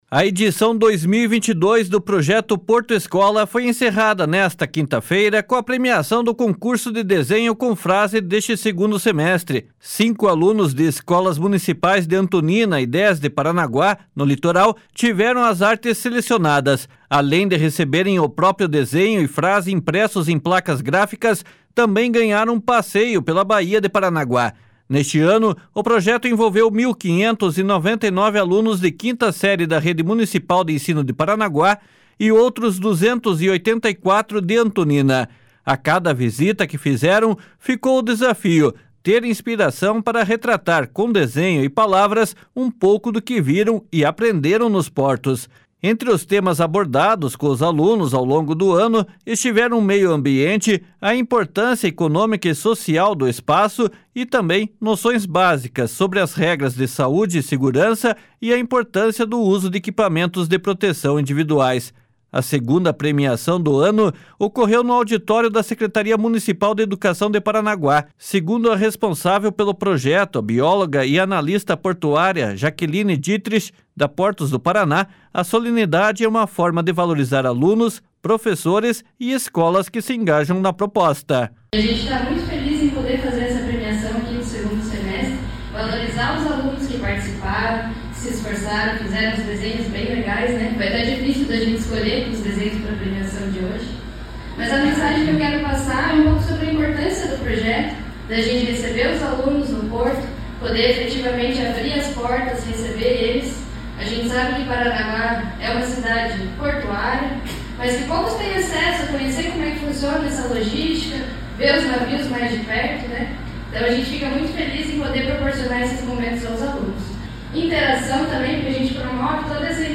A secretária municipal de Educação de Paranaguá, Tenile Cibele do Rocio Xavier, é uma das profissionais engajadas no projeto desde o início, em 2015.